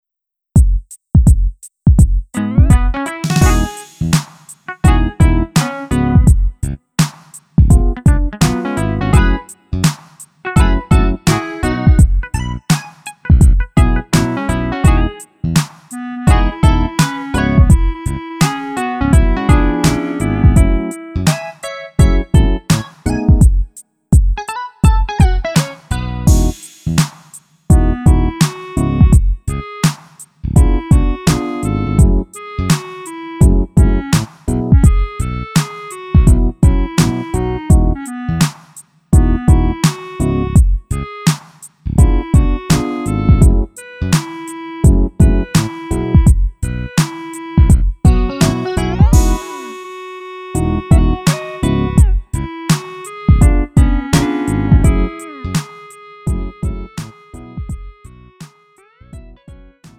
음정 원키 3:55
장르 구분 Lite MR